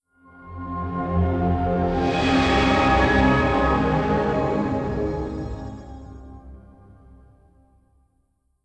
OS3 Warp 2.0 Shutdown.wav